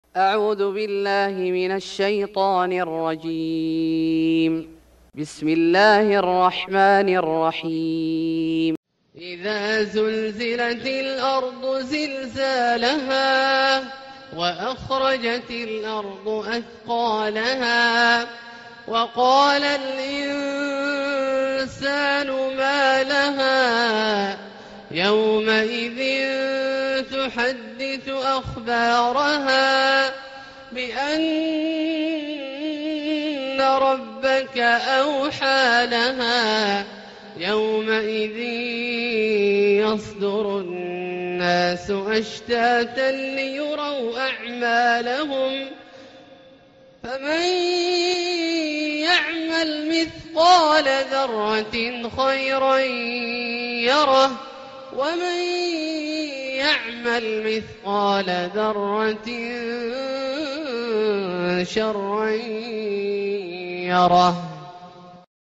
سورة الزلزلة Surat Az-Zalzalah > مصحف الشيخ عبدالله الجهني من الحرم المكي > المصحف - تلاوات الحرمين